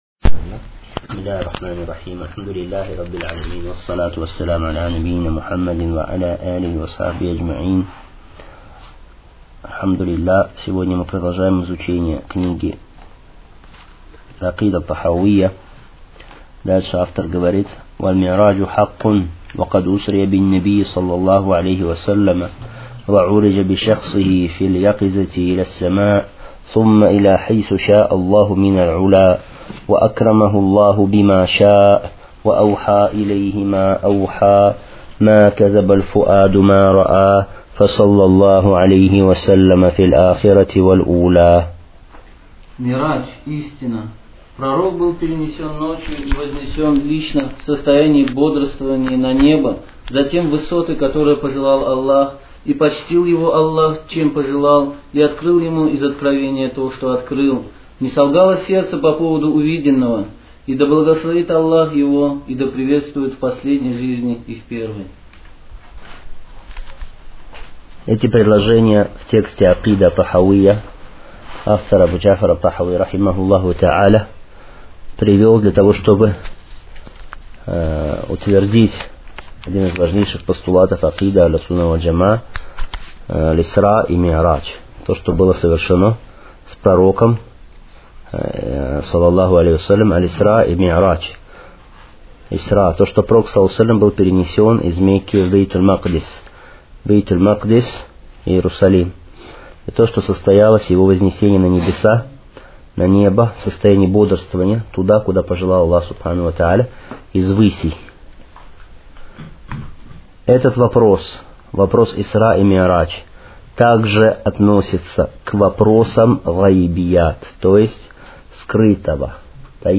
Серия уроков в пояснении книги «Акида Тахавия».